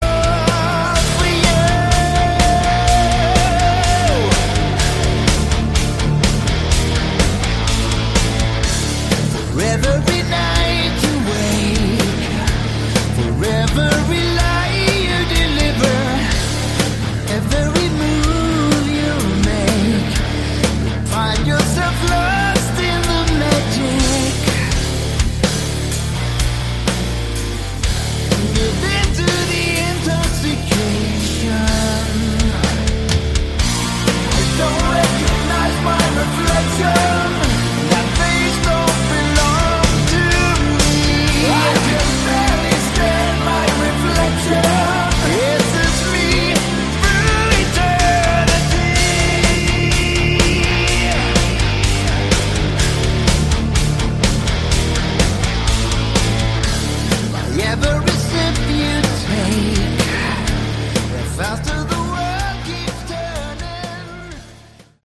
Category: Hard Rock/Melodic Metal
vocals, keyboards
guitars
bass
drums